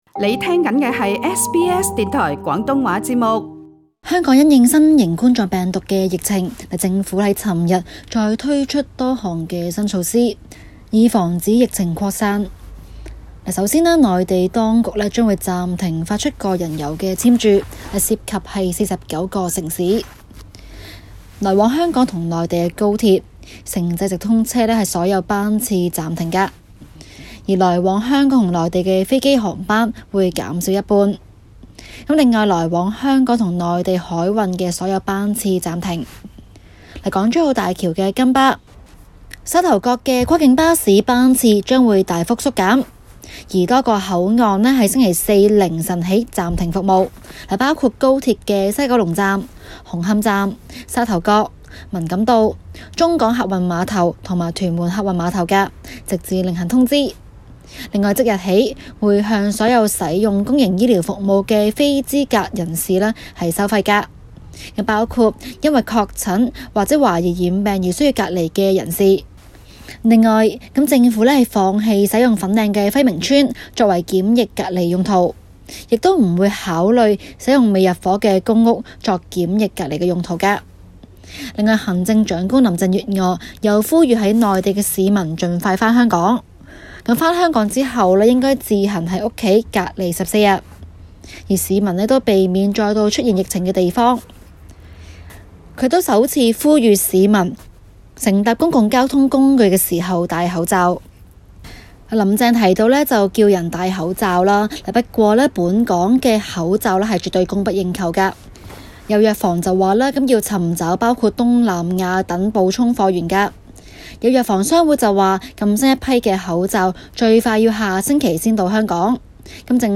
Source: AFP SBS廣東話節目 View Podcast Series Follow and Subscribe Apple Podcasts YouTube Spotify Download (10.75MB) Download the SBS Audio app Available on iOS and Android 香港為進一步控制新型冠狀病毒疫情，採取更多措拖。